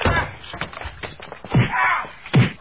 SFX弱弱的被打声音效下载
这是一个免费素材，欢迎下载；音效素材为弱弱的被打声， 格式为 mp3，大小1 MB，源文件无水印干扰，欢迎使用国外素材网。